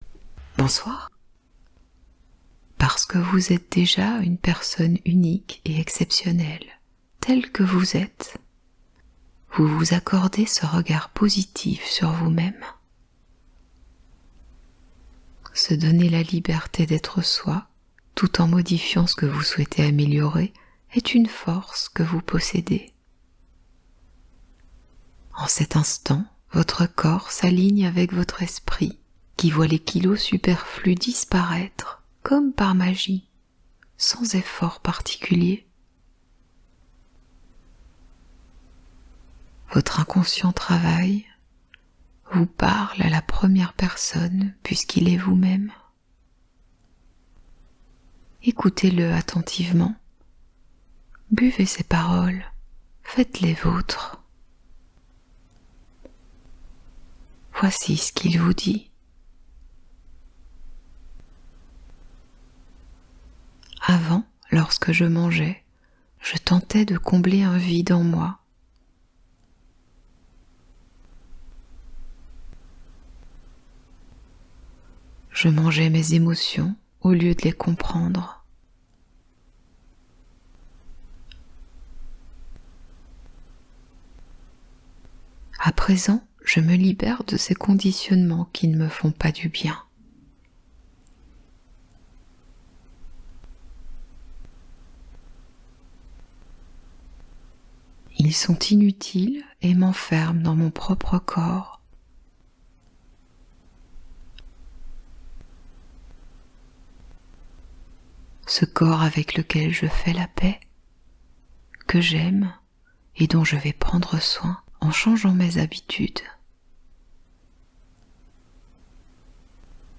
10 minutes pour maigrir en dormant | Routine hypnose quotidienne reprogrammation subconscient facile ✨